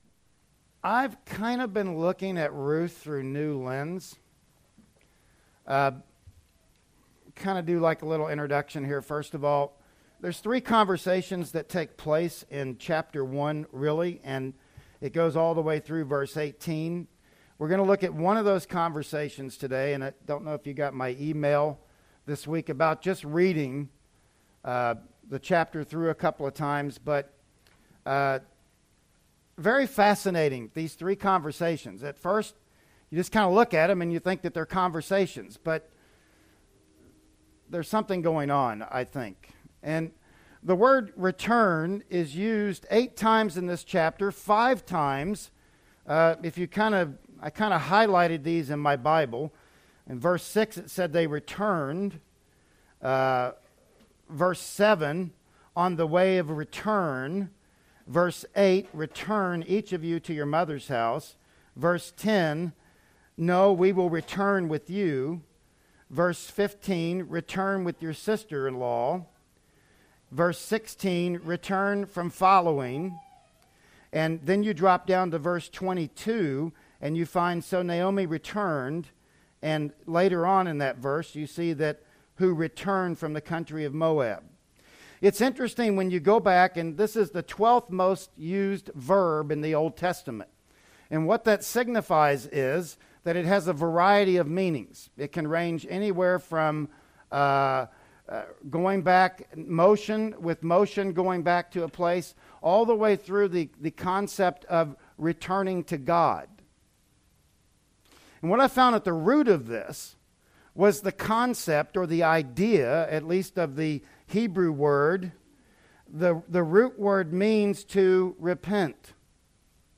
"Ruth 1:6-10" Service Type: Sunday Morning Worship Service Bible Text